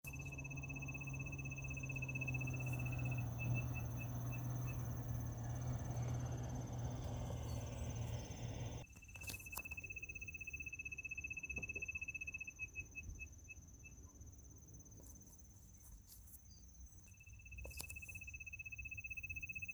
Spotted Nothura (Nothura maculosa)
Location or protected area: Concordia
Detailed location: Villa Zorraquin
Condition: Wild
Certainty: Observed, Recorded vocal